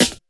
41 SD 02  -L.wav